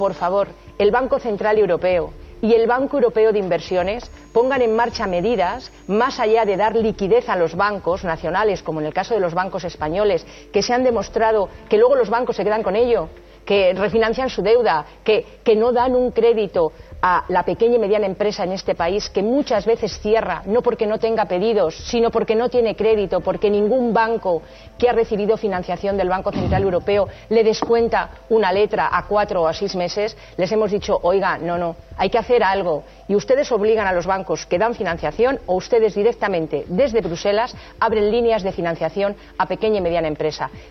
Fragmento de una entrevista en Las mañanas de Cuatro TV 2-7-2013